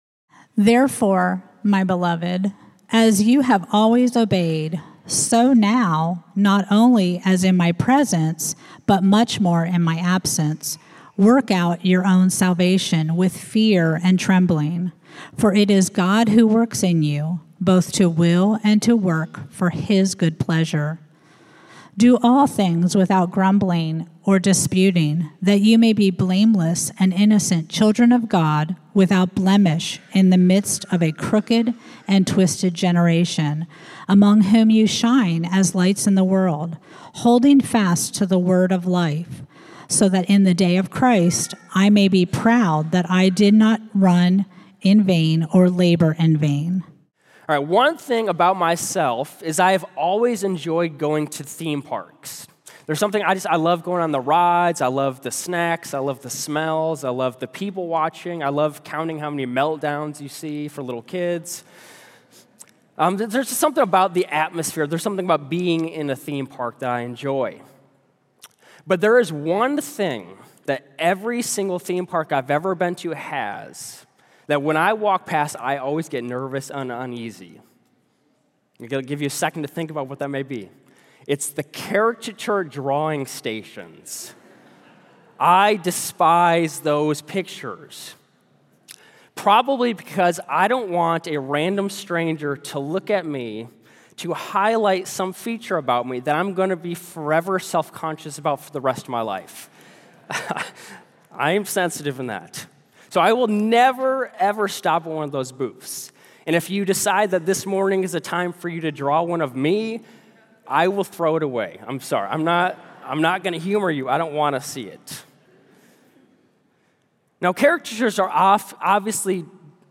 “ Gospel Joy “ A Series on Philippians Worship Notes Sermon Notes Sermon Notes